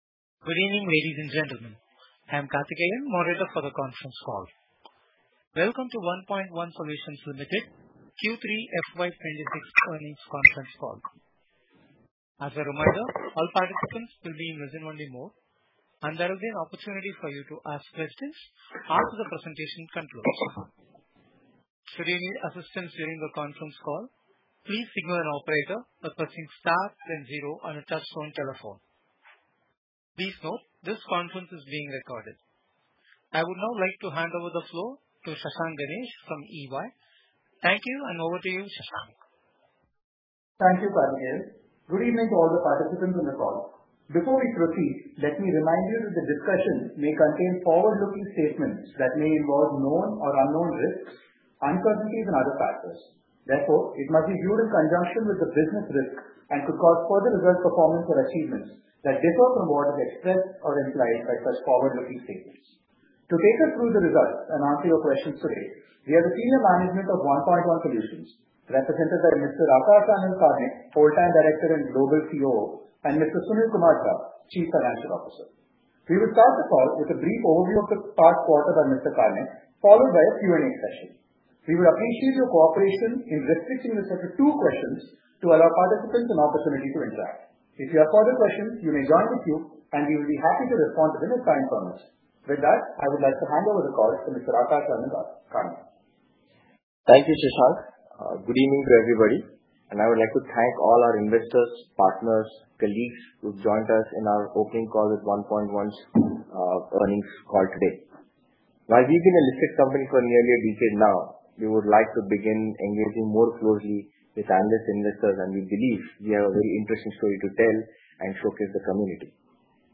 Analyst Call Audio